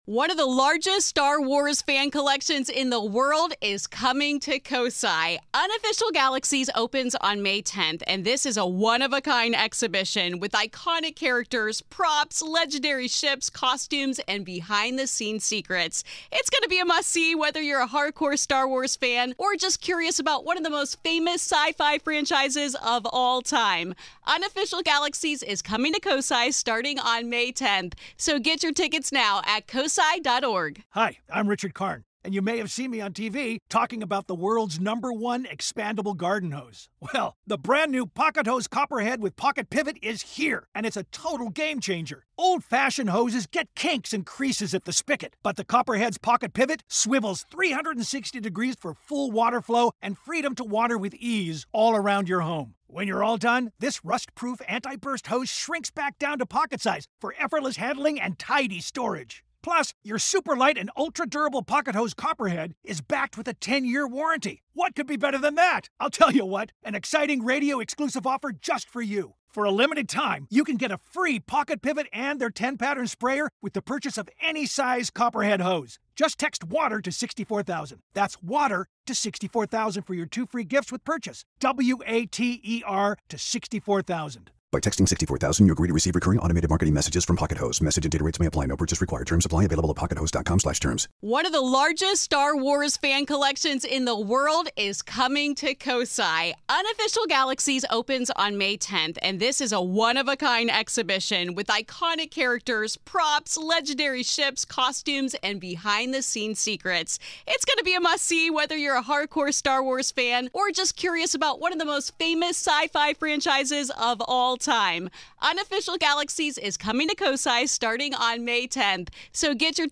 **There is some mild background noise in the first 14 mins which ive cleaned up that goes away, apologies folks**